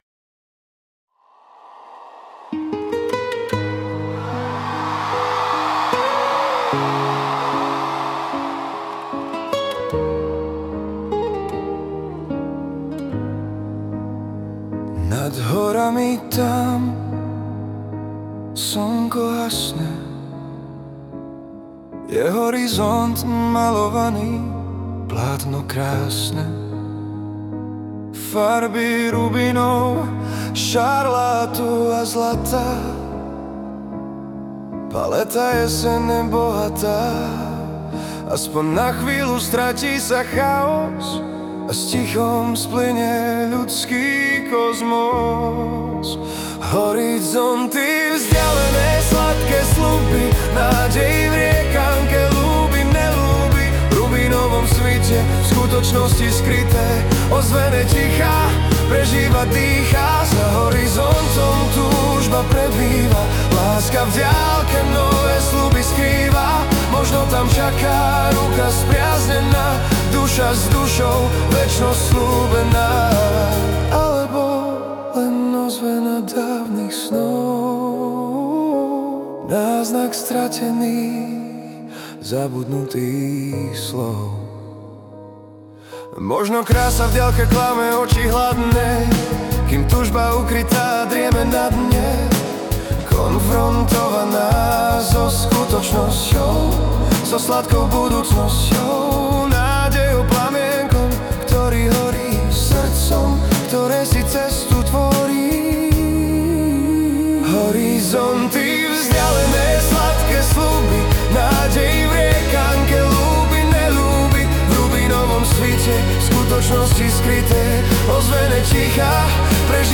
Hudba a spev AI
Balady, romance » Láska